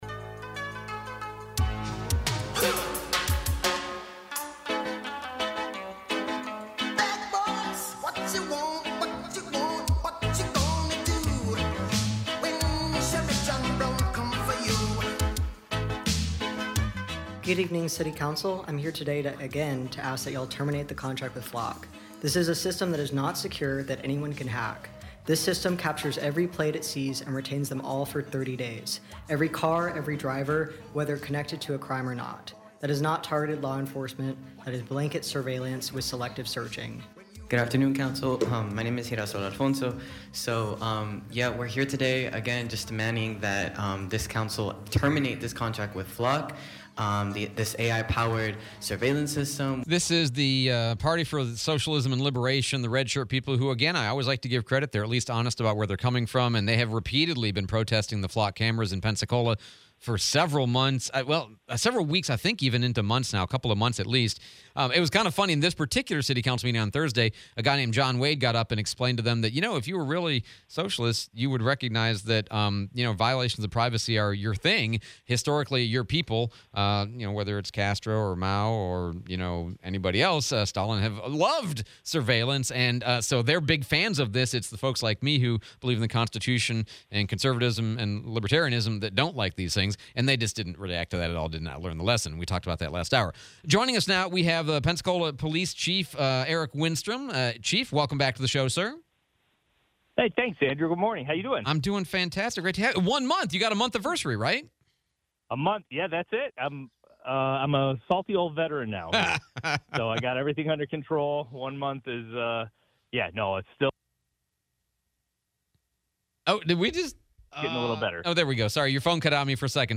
04/02/26 Interview with PPD Chief Eric Winstrom